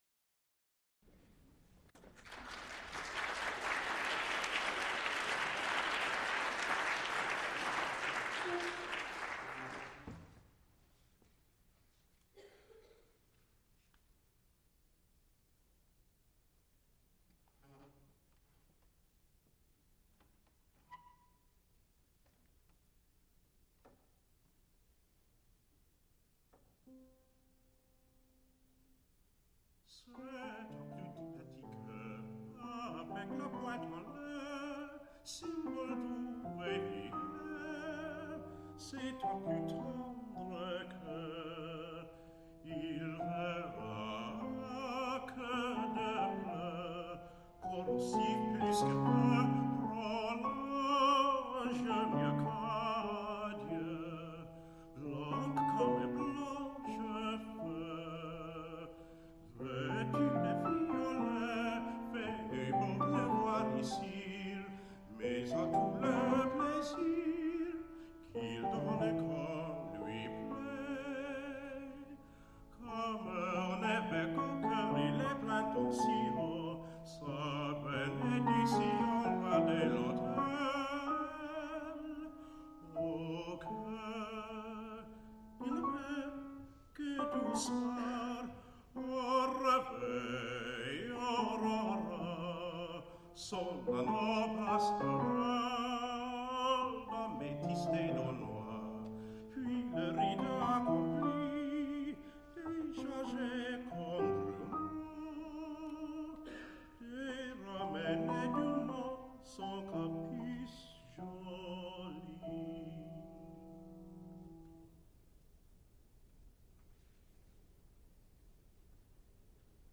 baritone
piano
Songs (Medium voice) with piano
Music--First performances